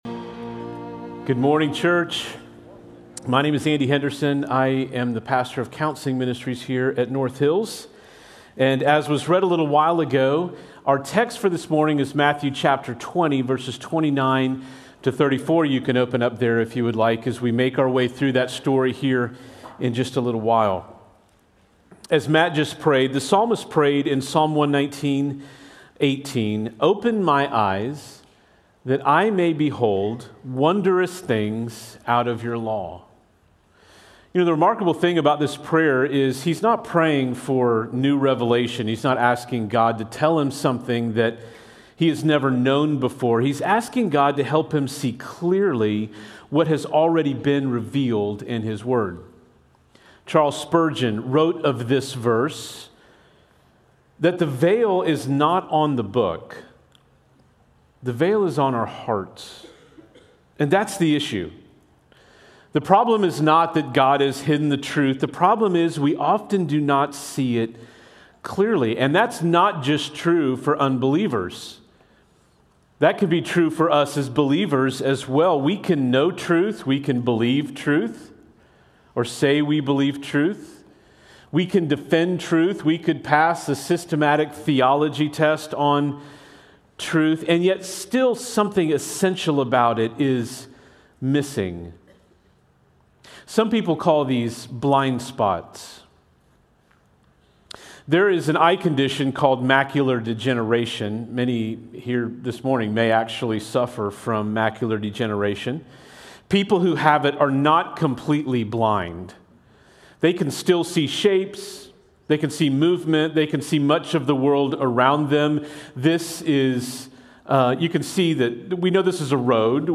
Prev Previous Sermon Next Sermon Next Title Open My Eyes